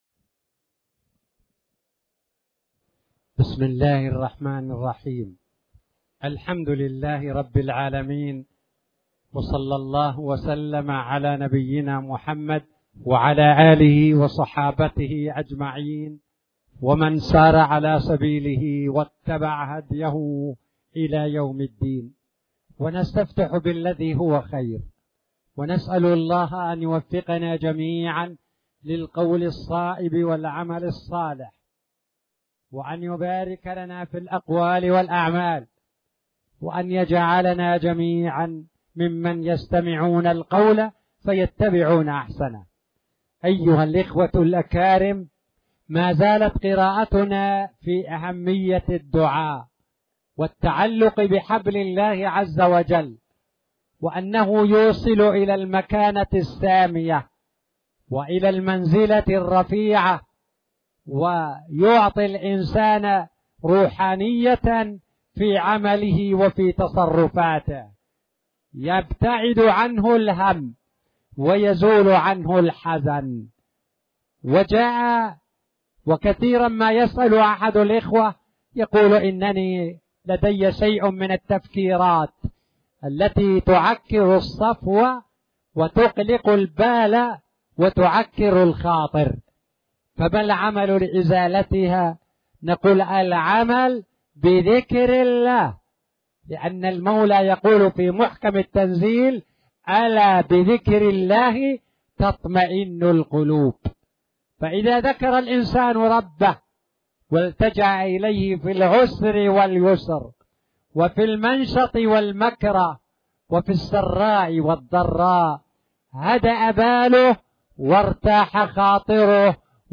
تاريخ النشر ١٩ جمادى الأولى ١٤٣٨ هـ المكان: المسجد الحرام الشيخ